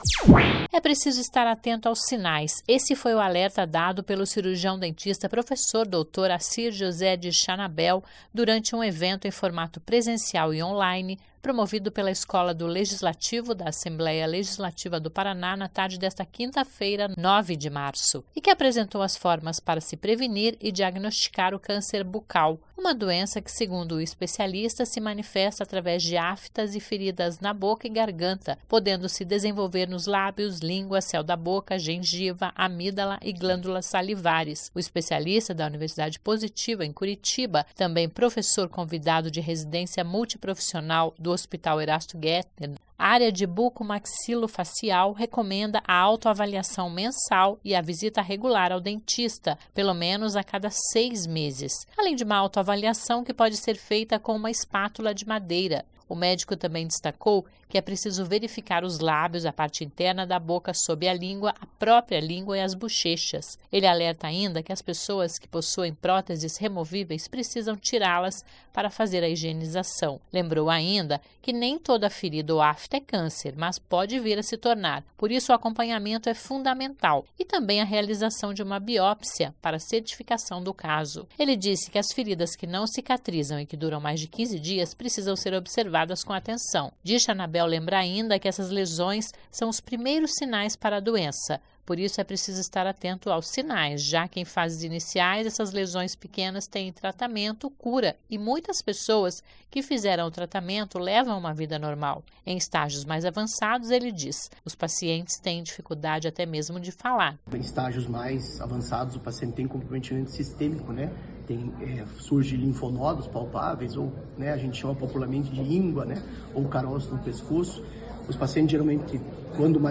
Evento aconteceu na tarde desta quinta-feira (9), no Auditório Legislativo.
(Sonora)